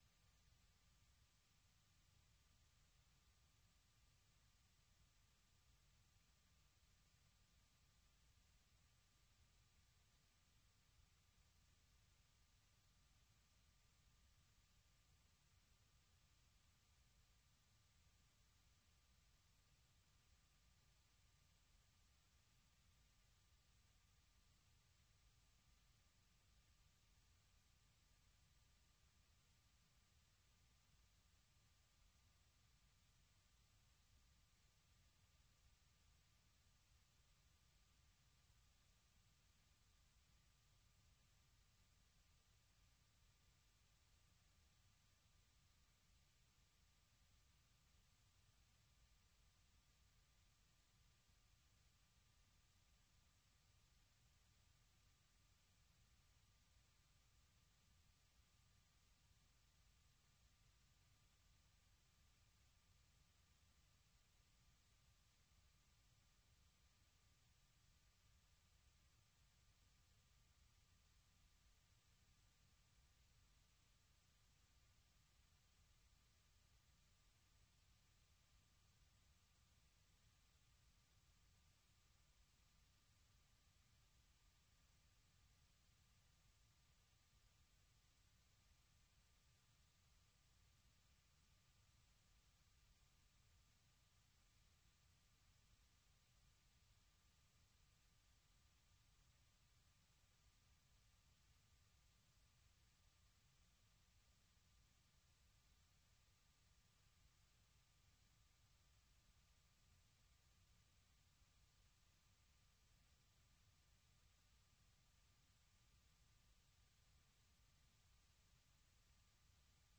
Allocution du président Donald Trump devant la session conjointe du Congrès